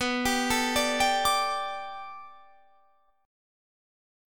Listen to Bm7#5 strummed